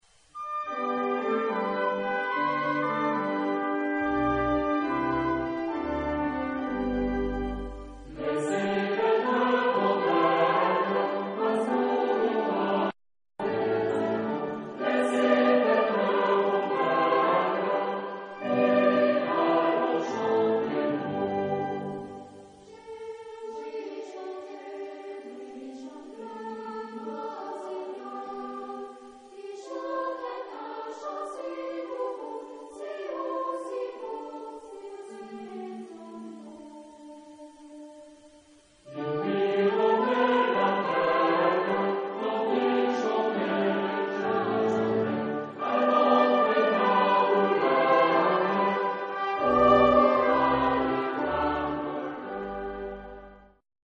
Genre-Stil-Form: weltlich ; Weihnachtslied ; Volkstümlich
Charakter des Stückes: schnell ; fröhlich
Instrumentation: Bläser + Orgel  (5 Instrumentalstimme(n))
Tonart(en): G-Dur